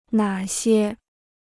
哪些 (nǎ xiē) พจนานุกรมจีนฟรี